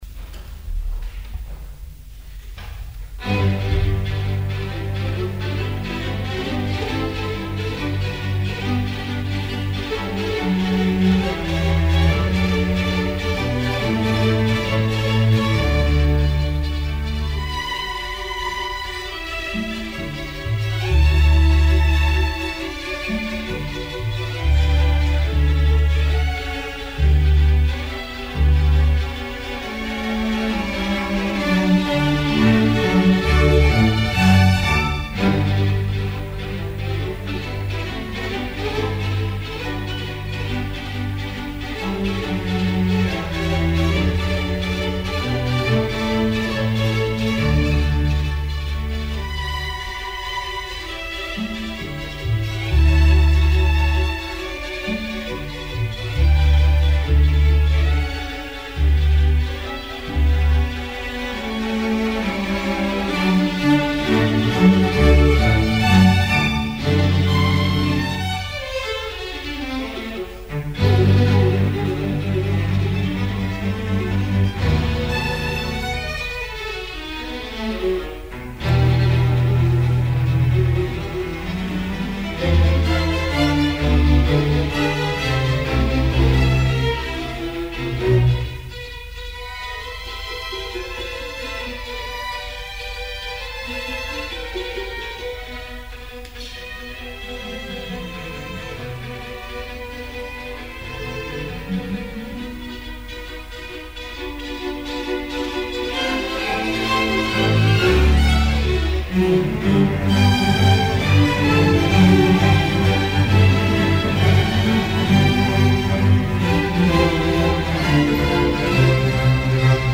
... des Studentischen Kammerorchesters der TU Braunschweig aus den Jahren 1970 ? bis 1979 (technischer Test).
Konzert am 10. Juni 1977: